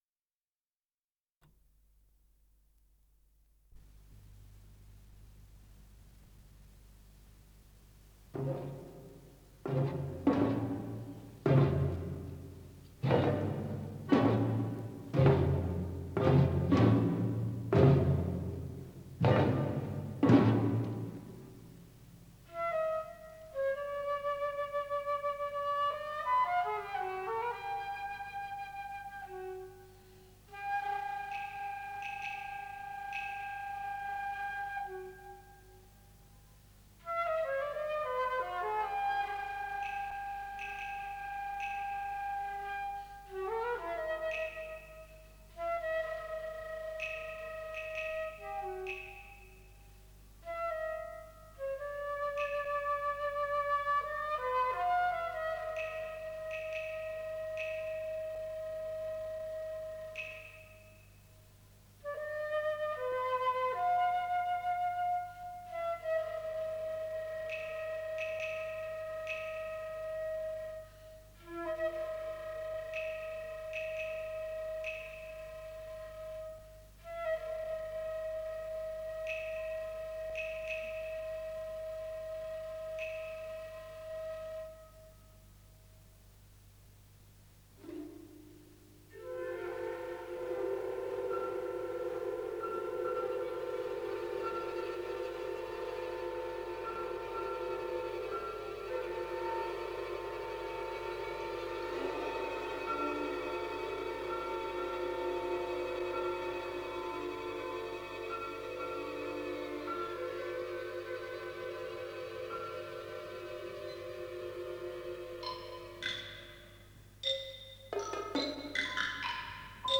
Музыка балета, 2 часть